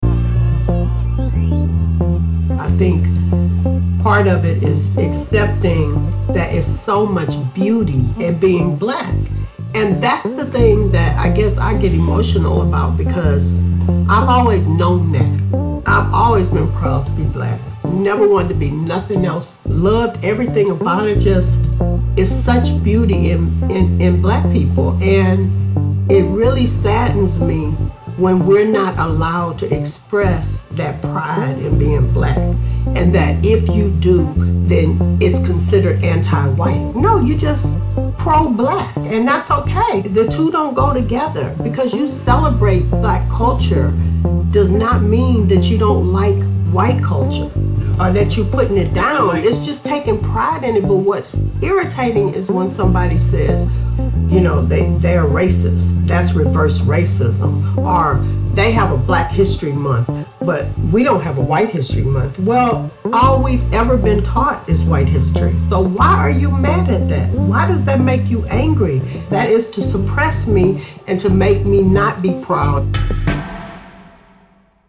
* R&B * September 30